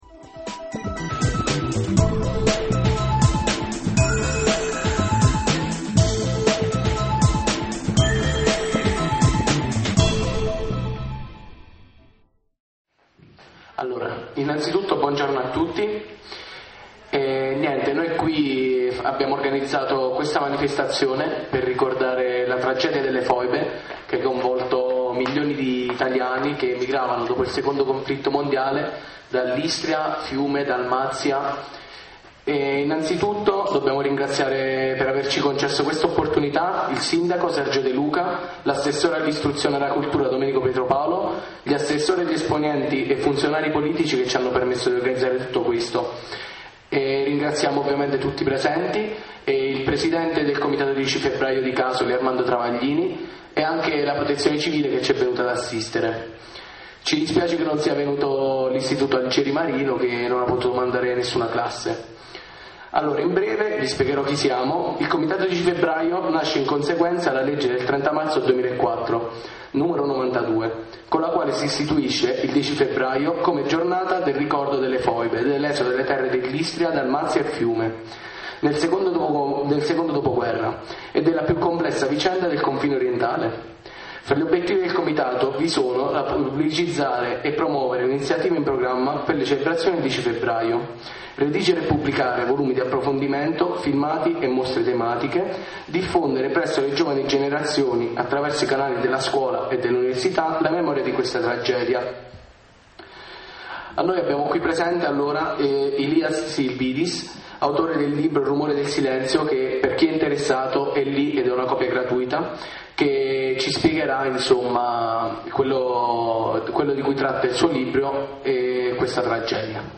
Ascolta il convegno sulla tragedia delle foibe
convegno_foibe_2010.mp3